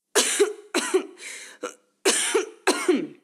Mujer tosiendo
toser
mujer
Sonidos: Acciones humanas
Sonidos: Voz humana